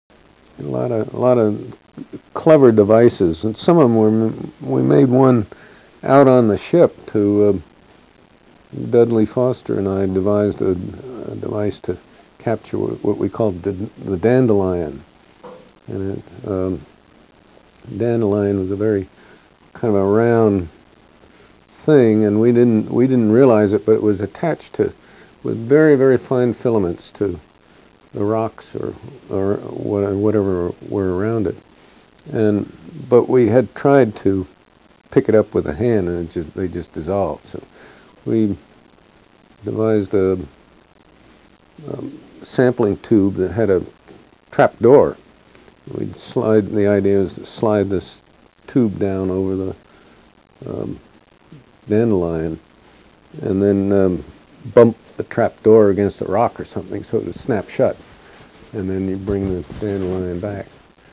From inside Alvin